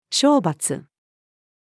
賞罰-female.mp3